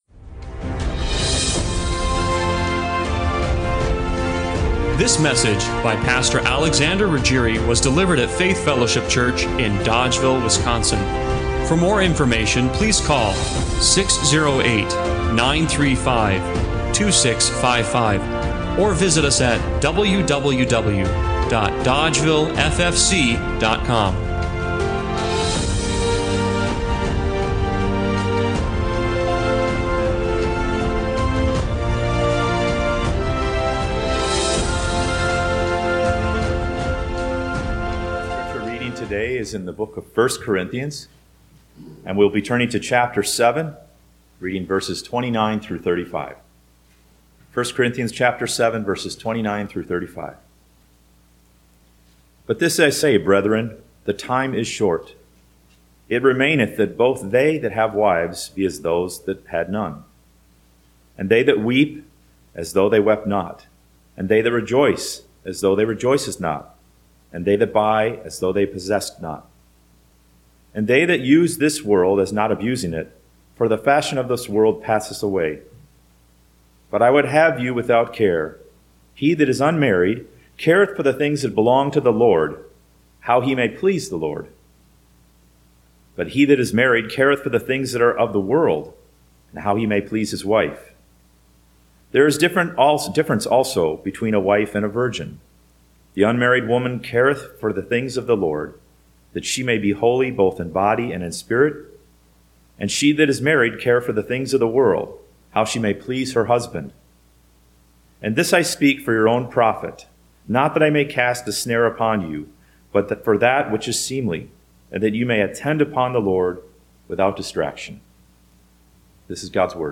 1 Corinthians 7:29-39 Service Type: Sunday Morning Worship Are you concerned about the things you’re concerned about?